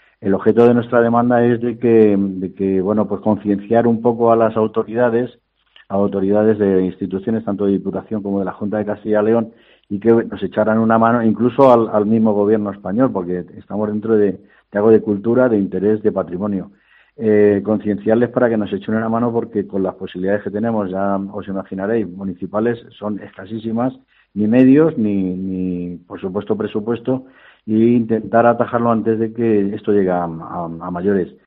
Así lo ha explicado a Cope Ávila, Vicente López, alcalde de La Horcajada.